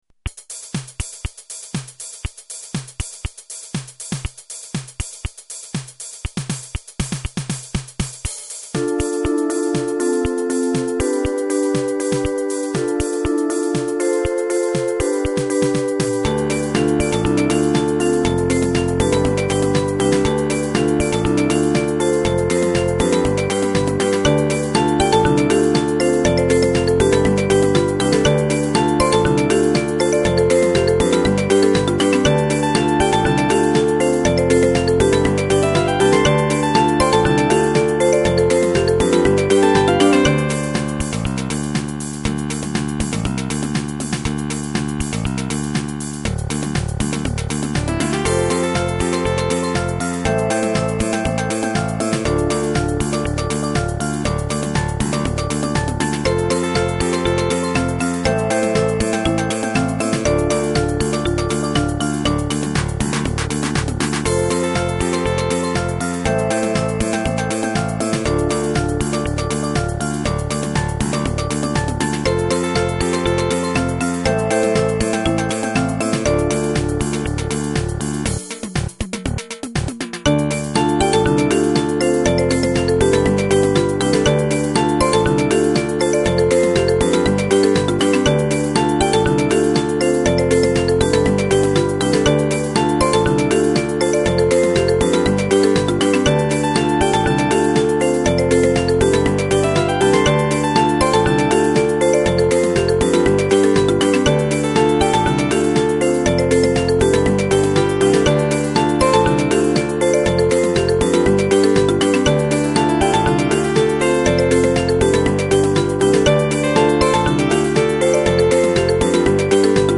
• Music has an ending (Doesn't loop)
Fades at the end